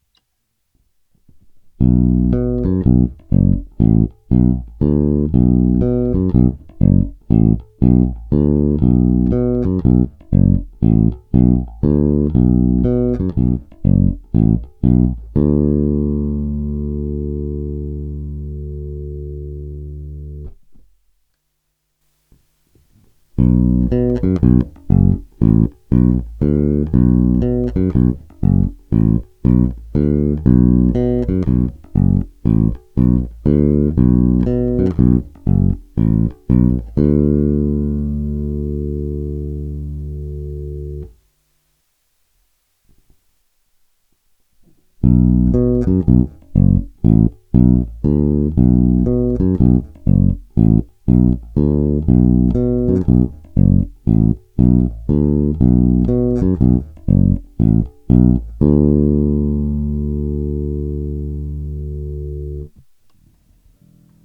Nahráváno přímo do zvukovky bez úprav, jen normalizováno. V první nahrávce jsou normalizované všechny části zvlášť, aby se vyrovnaly hlasitosti.
Pořadí je čtyři kola cívky sériově - čtyři kola pouze cívka blíže ke kobylce - čtyři kola cívky paralelně. Basa je lip(b)ová kopie Musicmana Apollo, pasivní, se snímačem Bartolini, celkem nové struny Dean Markley, roundwoundy, niklové.
Srovnané hlasitosti